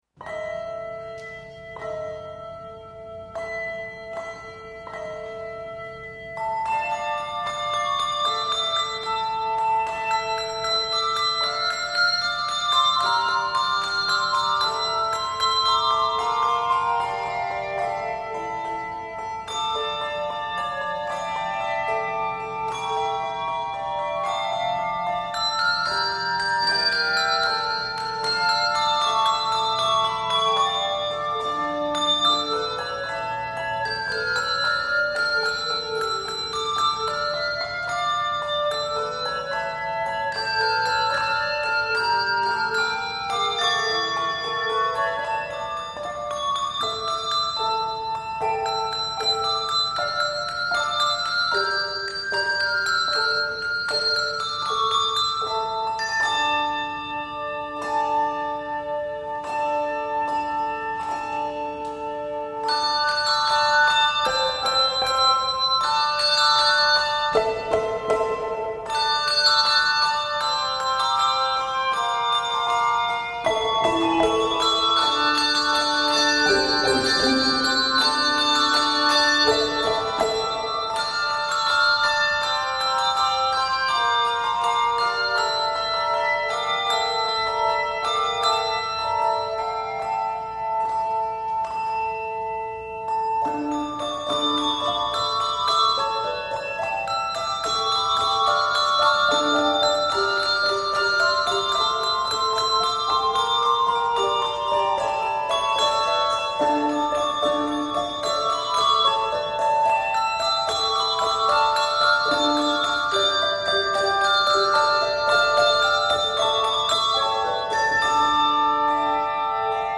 Octaves: 2-3 Level